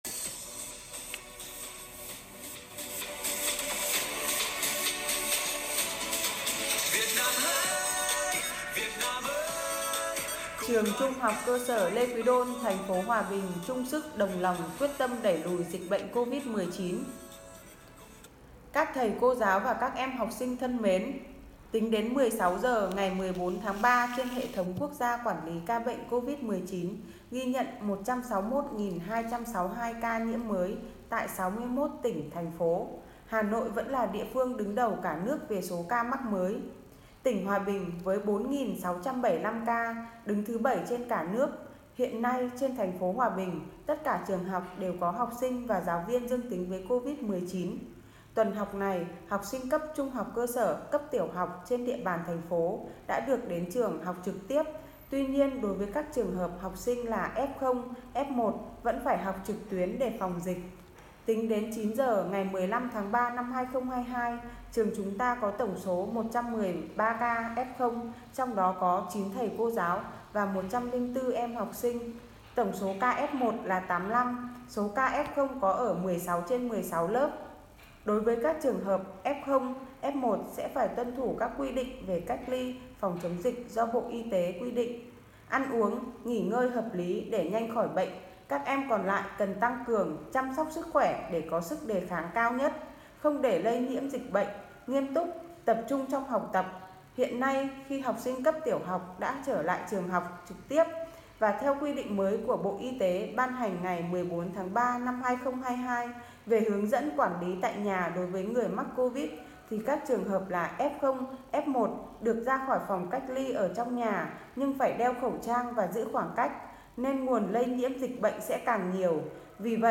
14.3.2022. Bản tin covid Trường THCS Lê Quý Đôn